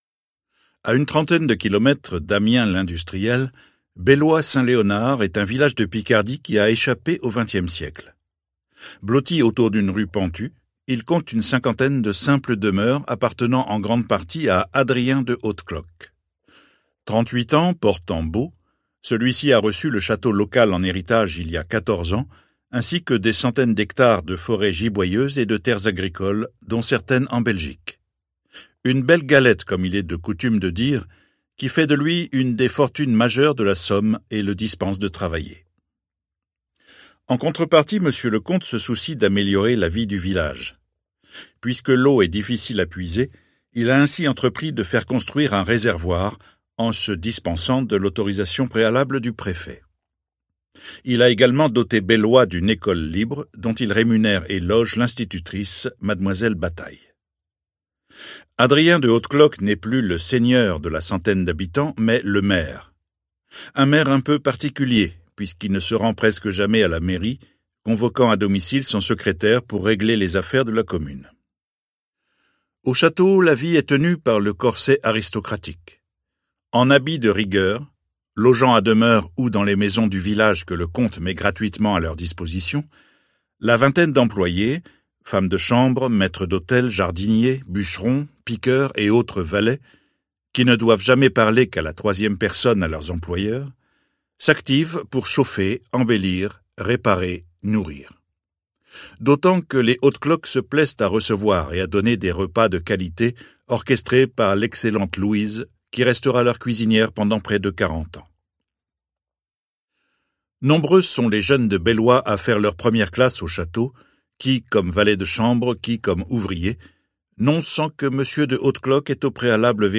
Livre audio Leclerc de Jean-Christophe Notin | Sixtrid